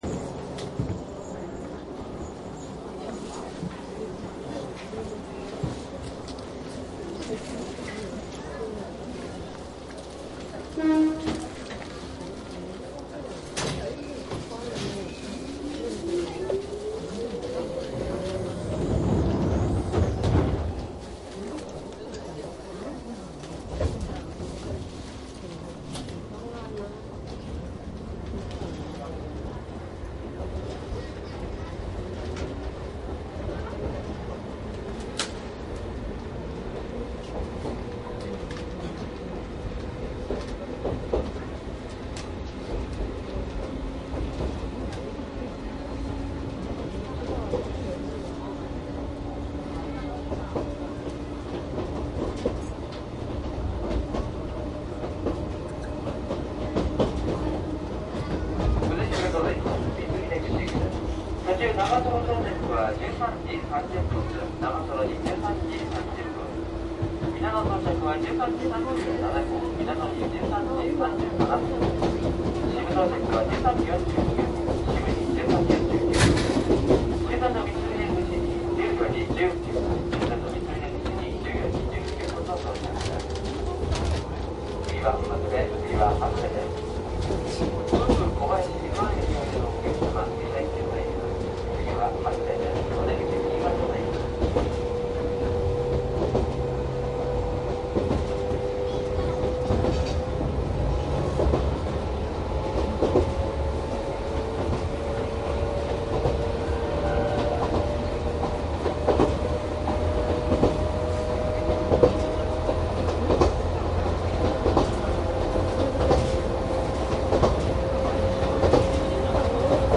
秩父鉄道1000系 走行音【車掌乗務】寄居～三峰口♪
車掌乗務なので自動放送一切入りません。録音当日は気温が25度近くまで上がったので窓があちこち開いてます。扇風機は回ってません。祭日の日中なので秩父あたりまで乗客がそこそこ居ます。
■【各停】寄居→三峰口 1103
マスター音源はデジタル44.1kHz16ビット（マイクＥＣＭ959）で、これを編集ソフトでＣＤに焼いたものです。